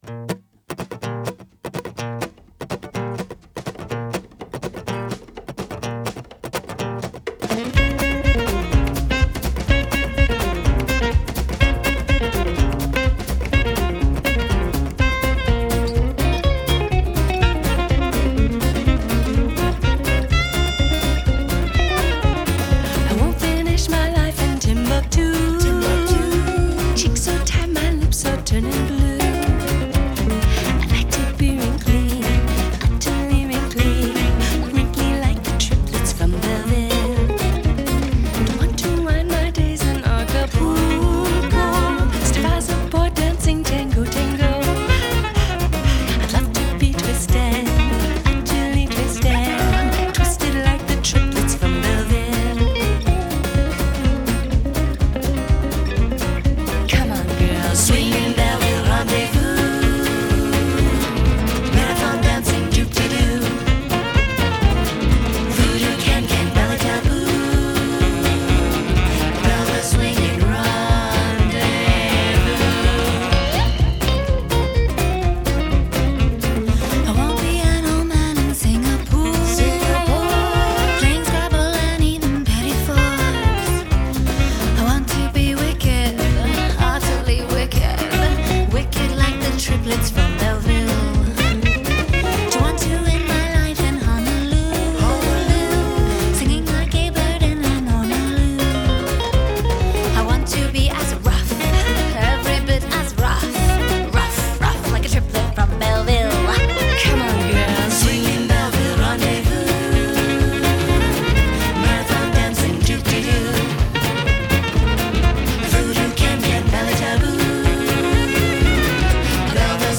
standup bass, piano, guitar, vocals
drums, percussion.
Genre: Jazz Vocals, Gipsy Swing